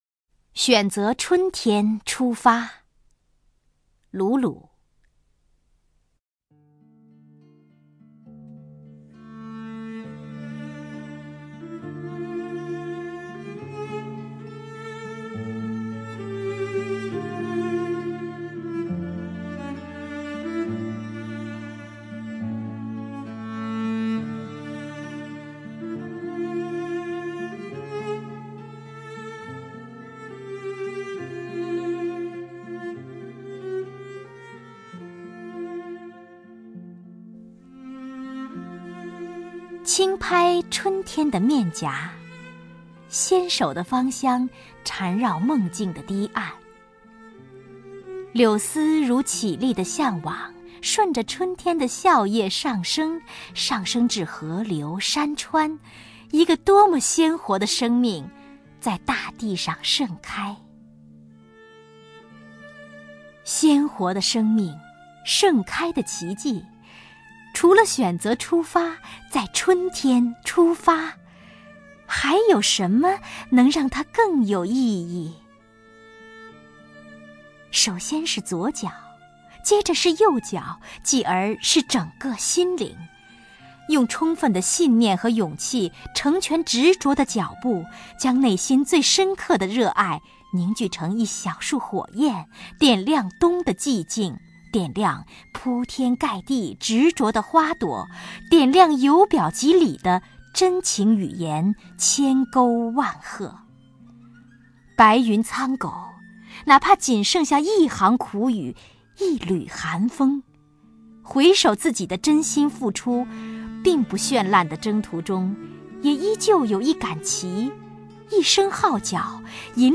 首页 视听 名家朗诵欣赏 王雪纯
王雪纯朗诵：《选择春天出发》(鲁橹)